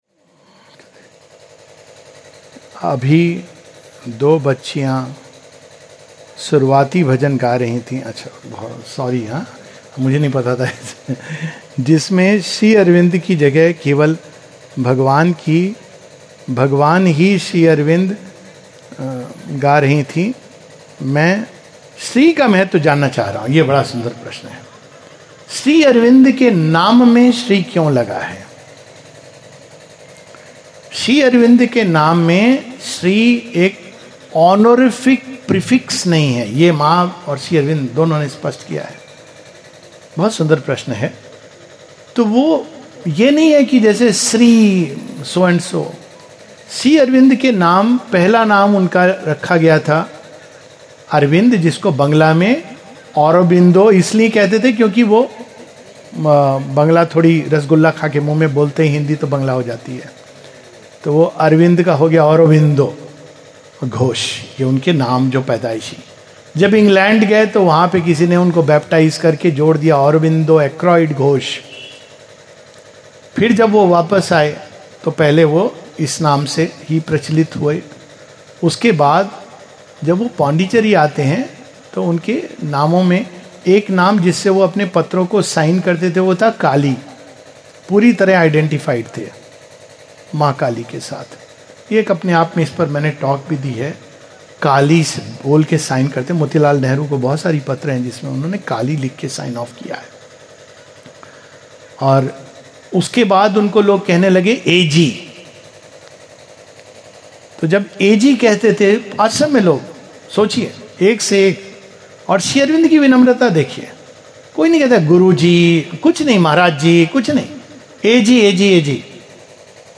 [The Significance of Sri Aurobindo's Name] From a Q&A session at the Sri Aurobindo Gram, Mehsua, MP.